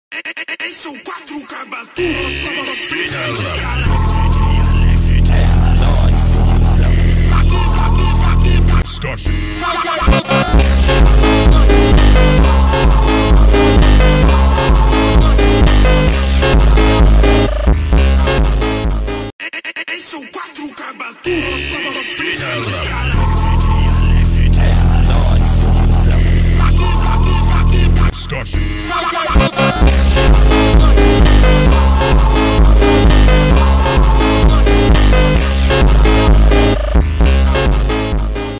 Bosster 16 Static Fire Sound Effects Free Download
Bosster 16 static fire VS ship 37 static fire of 1 raptor engine